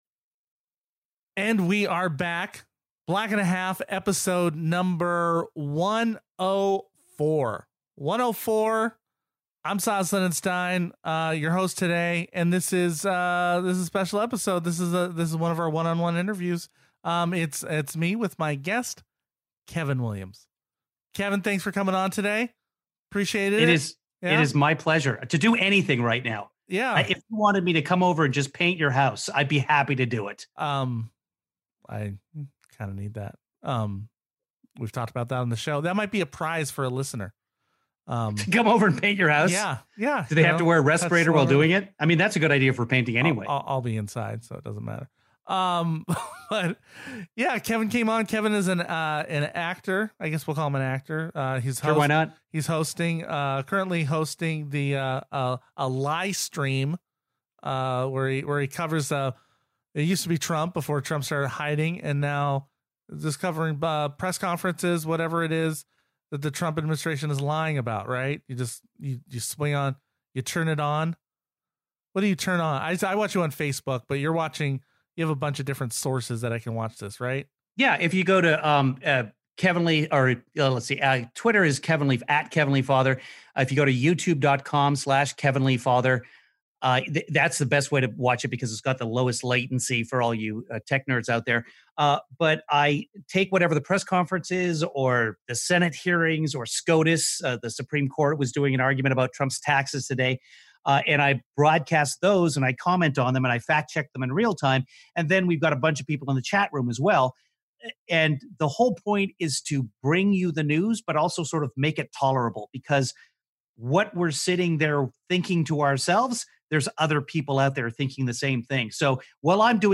one on one interview